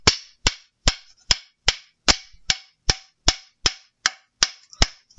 碰撞音效
描述：碰撞音效
标签： 墙壁 地面 击打 音效 身体 碰撞
声道立体声